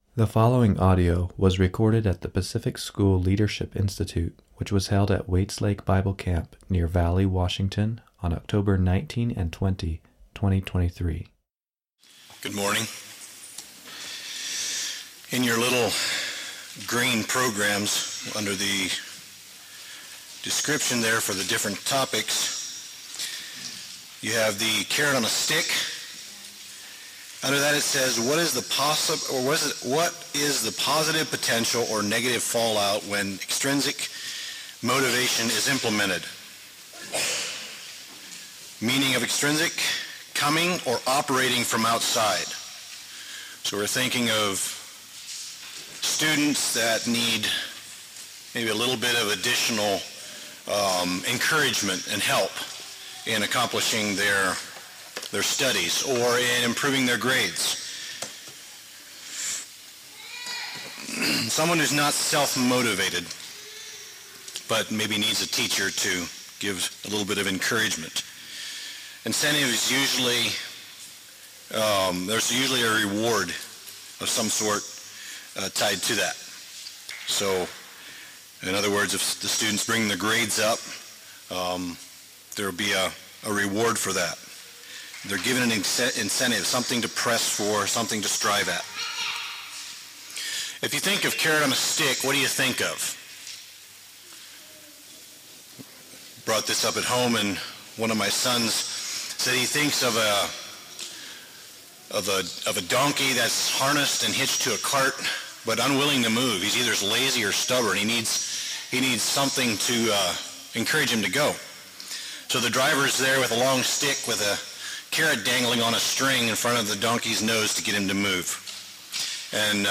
Short Talk: Carrot On A Stick?